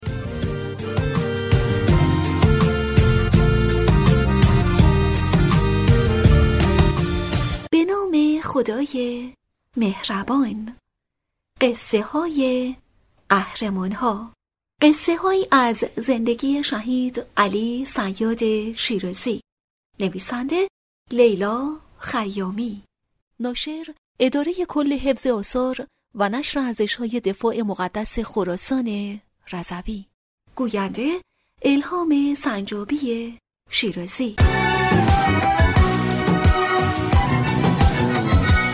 معرفی کتاب صوتی«بریز و بپاش»